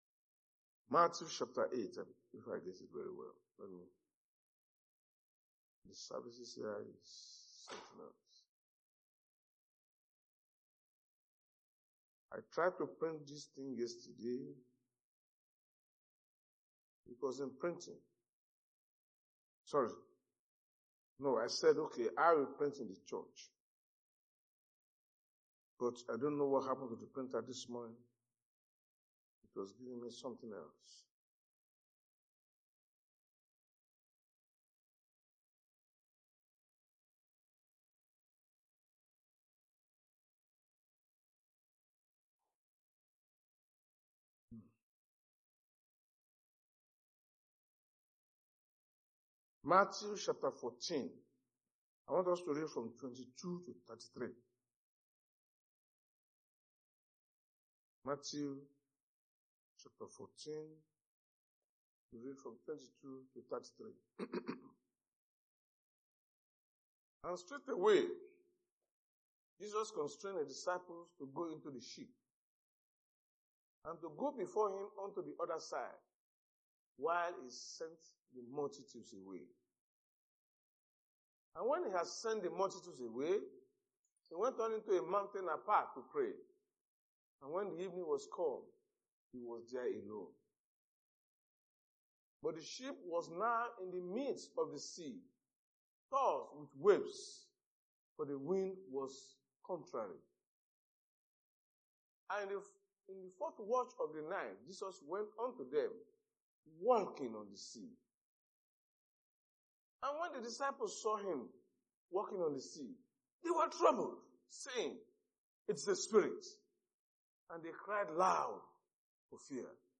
Sunday Sermon: Overcoming Laws & Limits